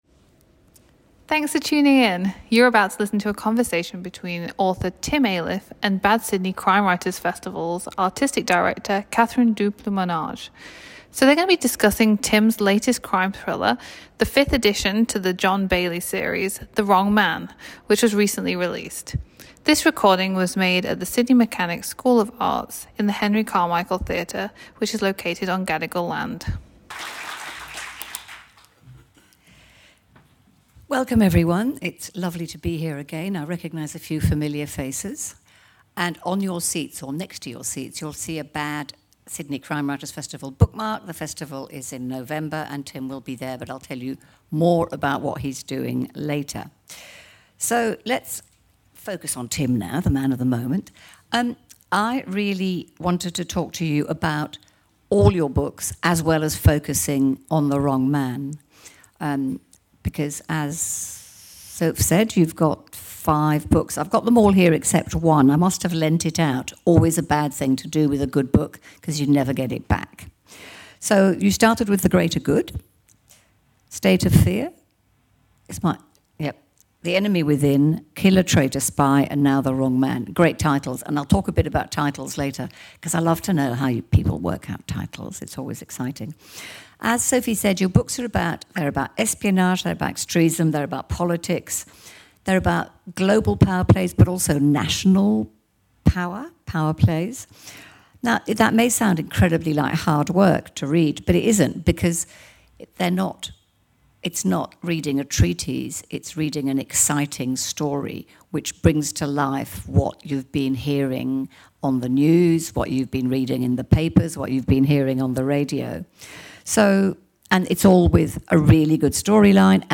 Event Recording